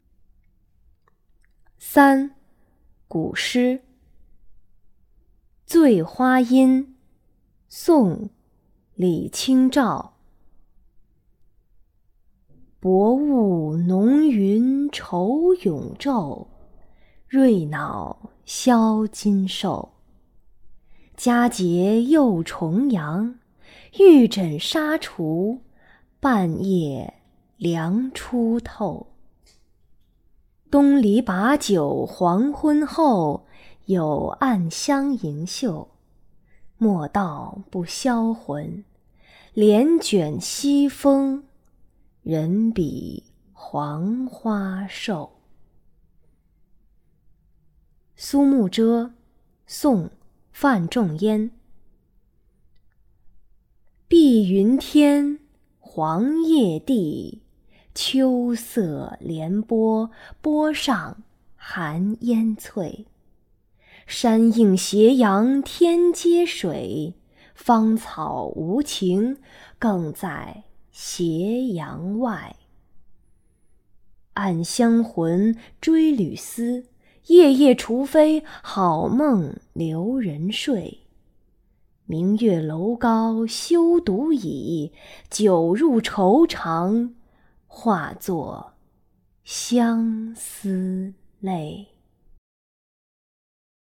领读课件
古诗 醉花阴